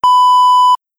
beep-01a.aiff